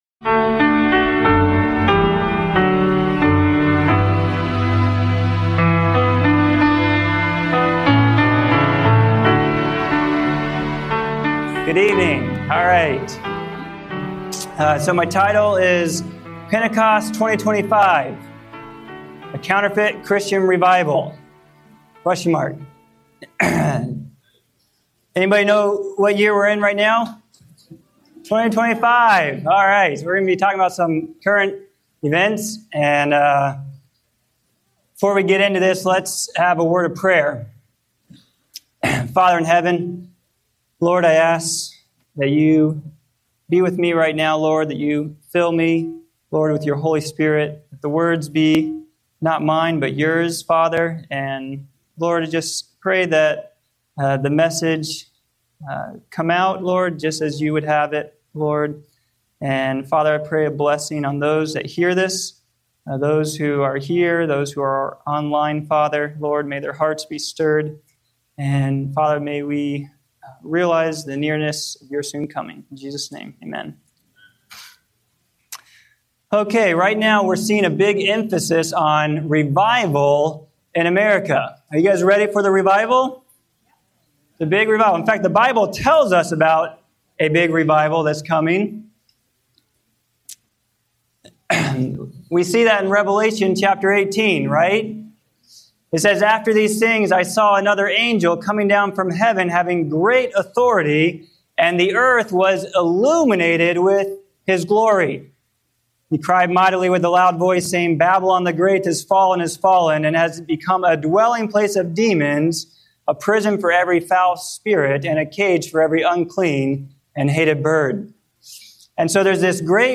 As talk of a 2025 revival grows, this sermon urges believers to seek true spiritual renewal—not emotional hype—through scripture, prayer, and personal transformation. With a call for discernment, unity, and revival beginning at home, it challenges Christians to pursue authenticity over sensationalism.